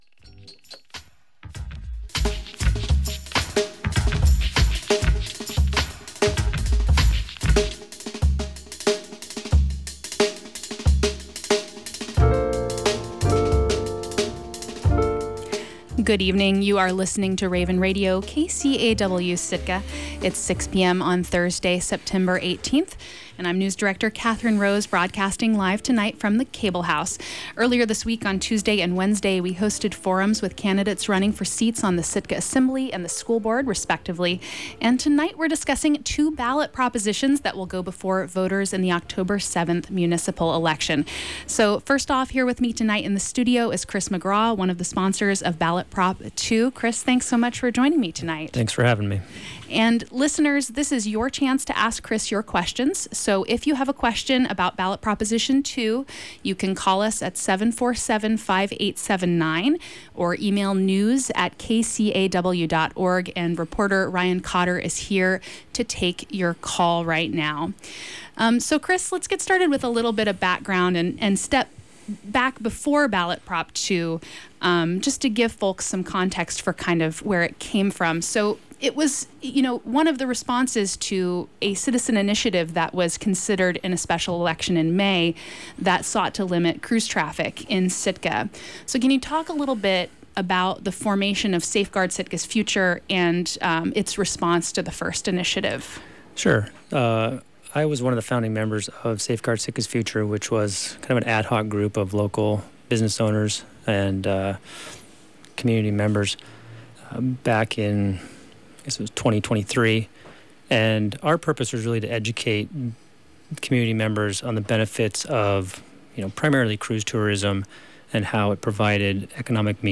Assembly Candidate Forum:Tuesday, September 16, 6:00-7:30 p.m.Listen Here